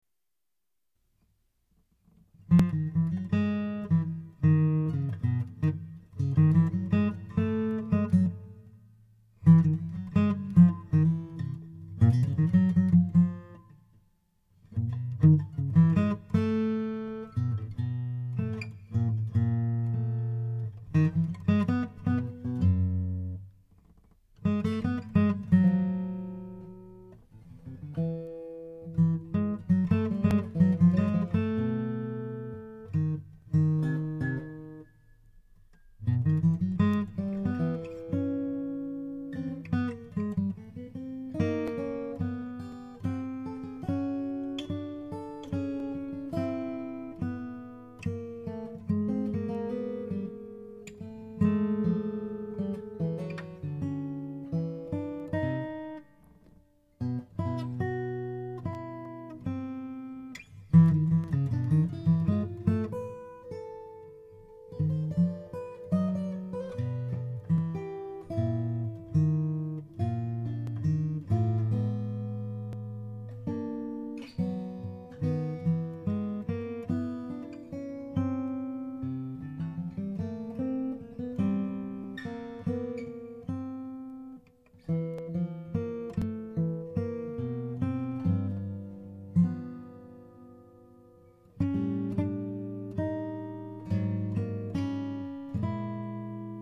Duet for Two Guitars